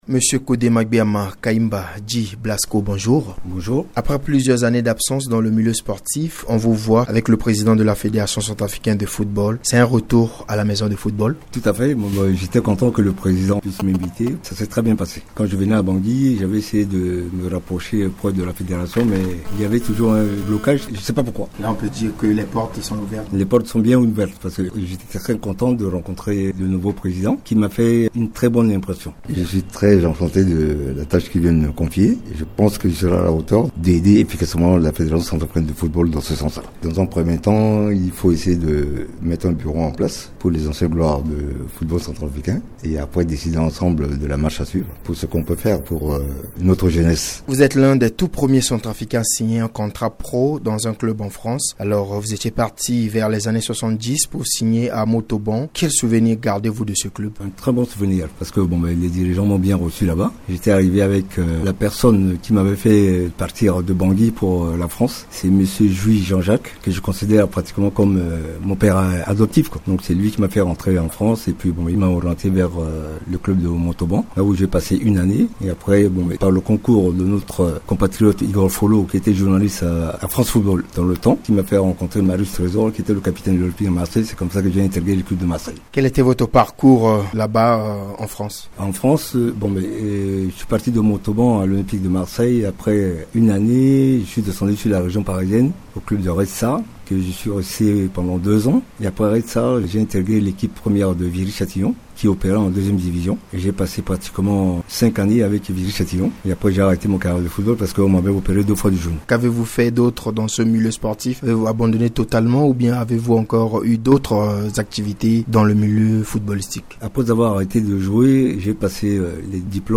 dans un échange avec Radio Ndeke Luka se prononce sur la pratique actuel du football en Centrafrique.